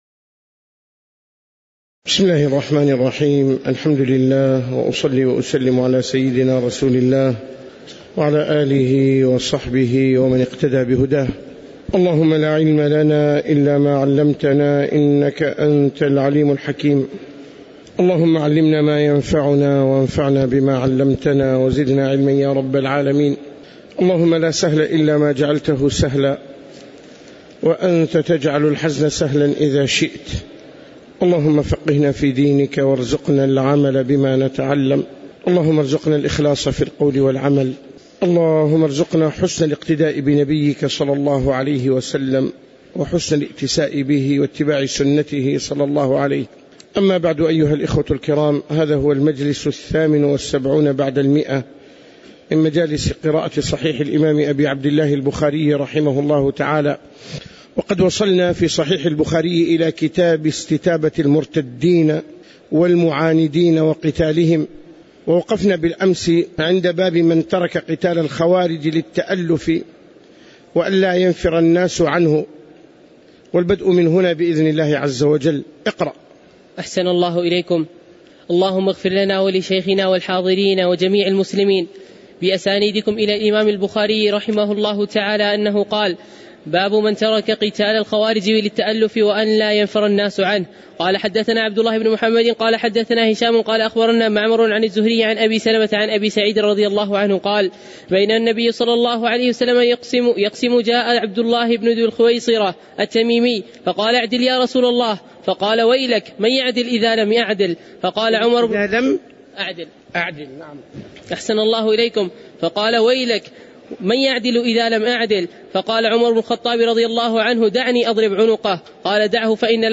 تاريخ النشر ٩ ربيع الأول ١٤٣٩ هـ المكان: المسجد النبوي الشيخ